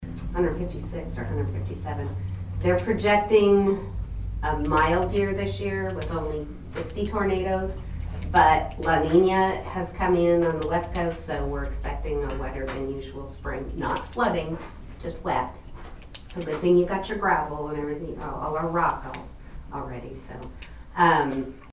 The Nowata County Commissioners met for their weekly meeting on Monday morning at the Nowata County Courthouse Annex.